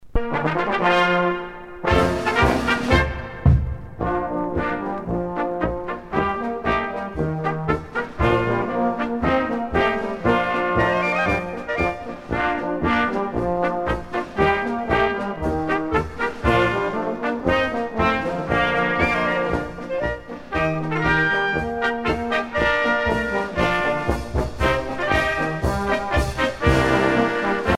danse : marche-polka
Pièce musicale éditée